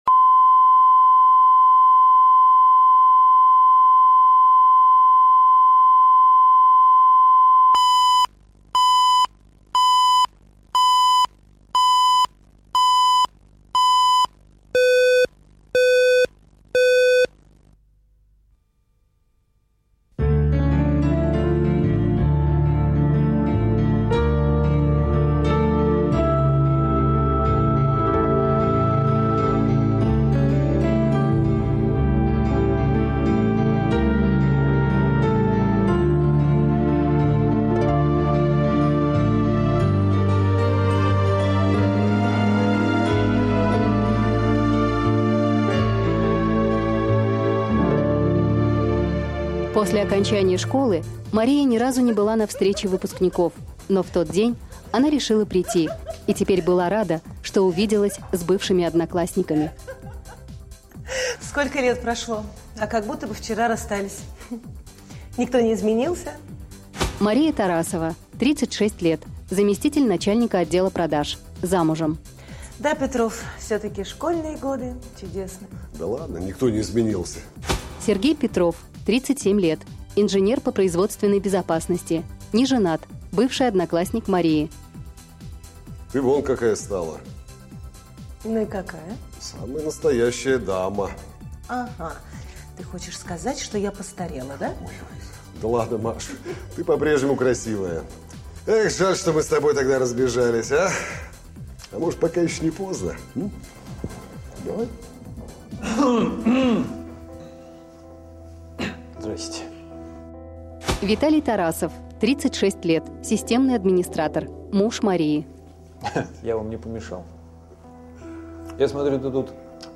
Аудиокнига Любовь по переписке | Библиотека аудиокниг
Прослушать и бесплатно скачать фрагмент аудиокниги